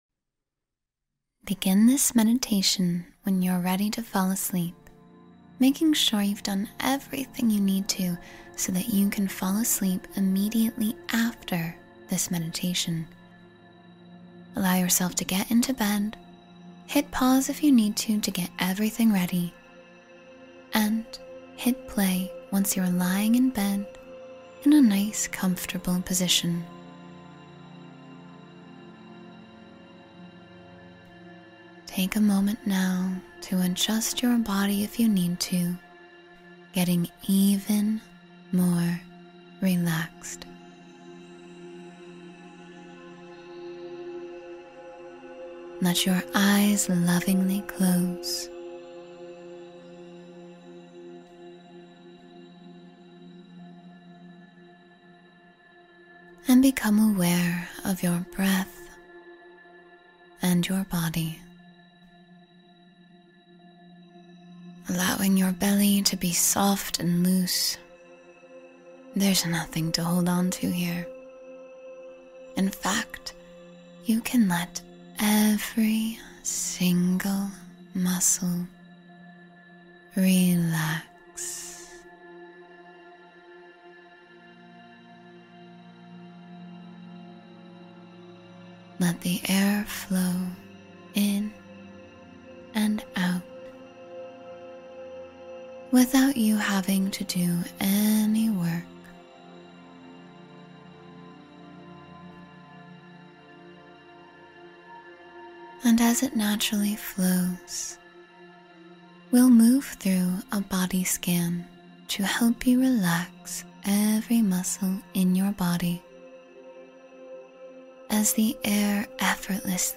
Sleep Peacefully and Drift Away Tonight — Guided Meditation for Rest and Relaxation